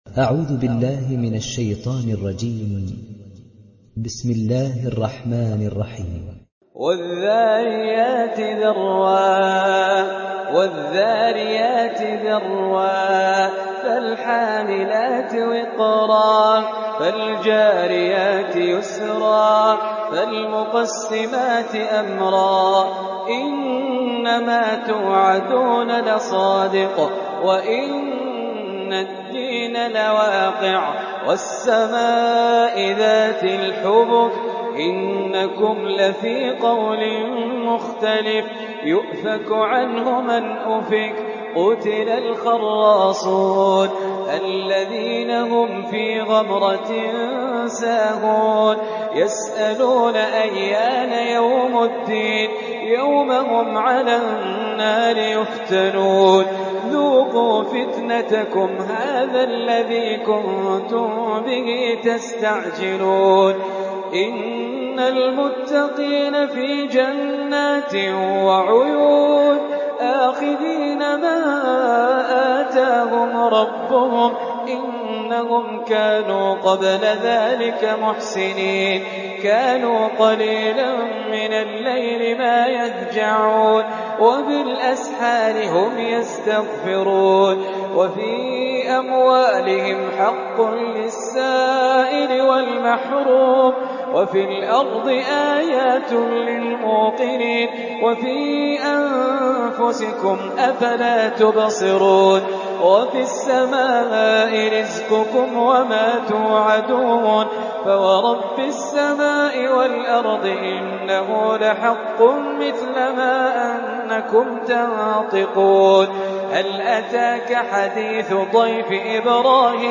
Riwayat Hafs